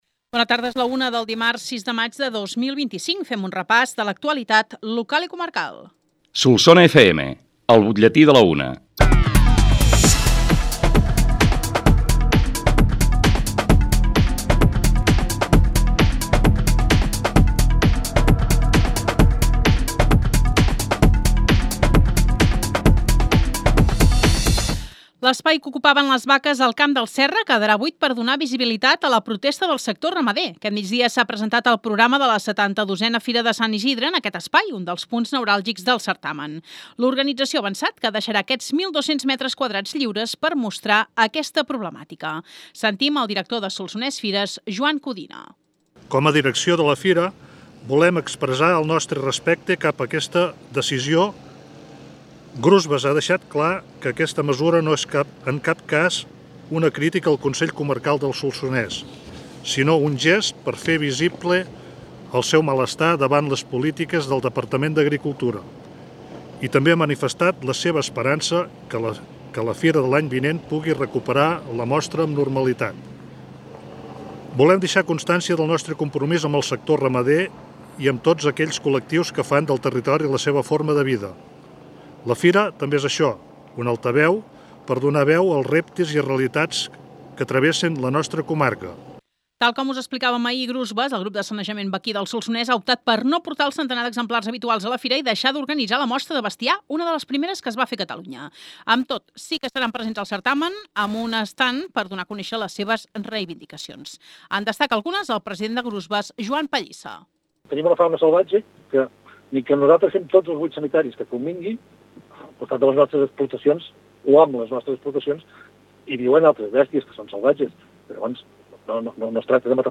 L’ÚLTIM BUTLLETÍ
BUTLLETI-6-MAIG-25.mp3